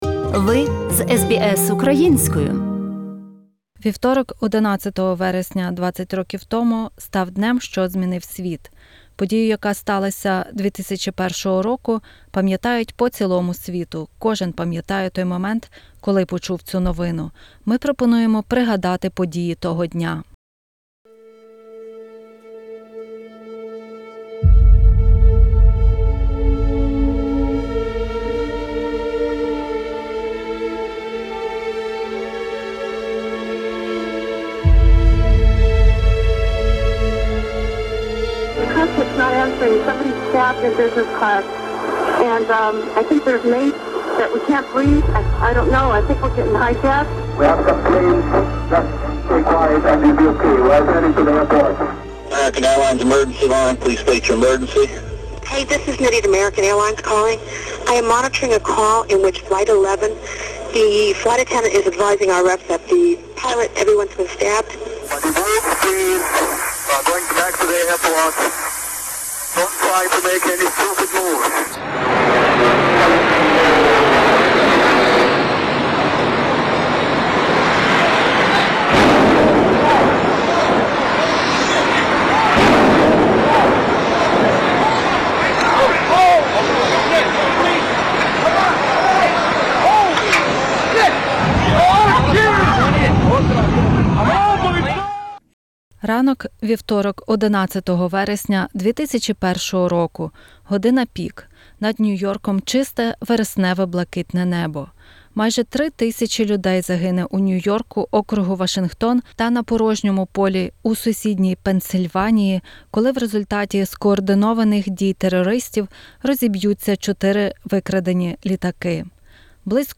У нашій програмі унікальні записи хроніки подій, які відбувалися під час терористичної атаки в Сполучених Штатах в той день.